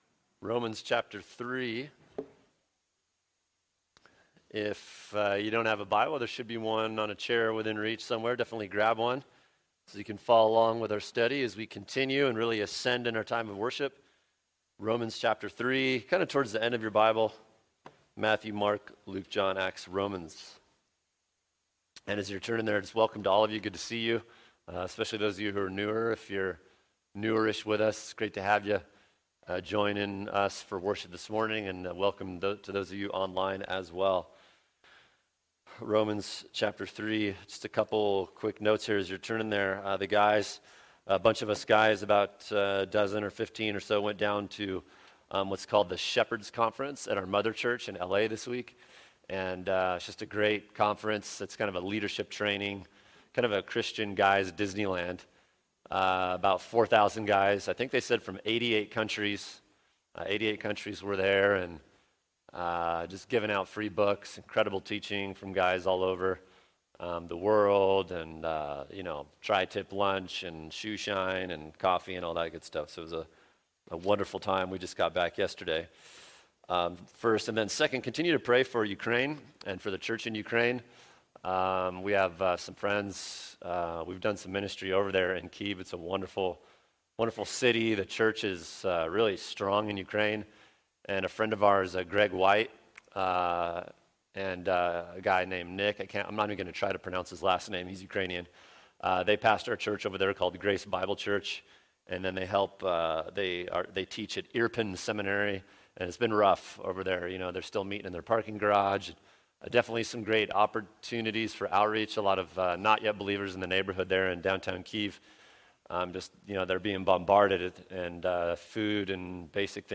[sermon] Romans 3:9-11 The Human Condition: The Depraved Nature | Cornerstone Church - Jackson Hole